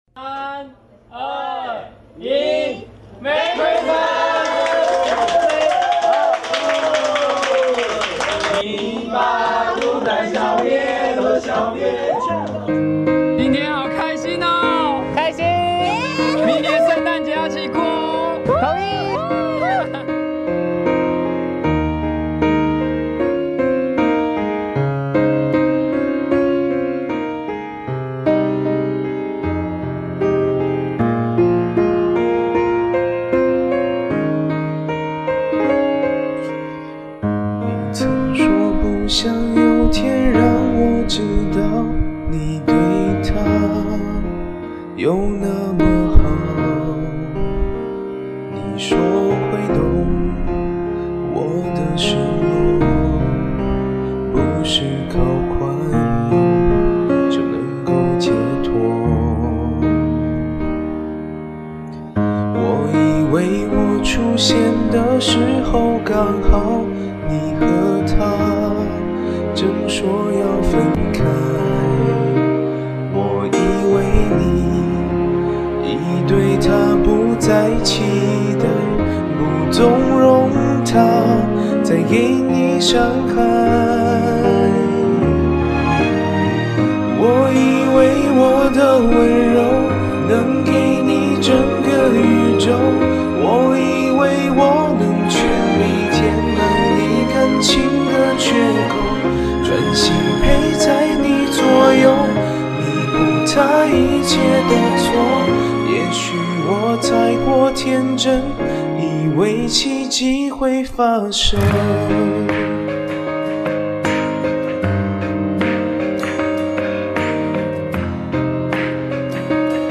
感冒了。。鼻子有点塞。。然后就气不足了。。 身体状态不佳。
um…这种小回音的效果咋弄的？好Pro…
你这感冒鼻塞的状态 把这首唱的很有feel啊……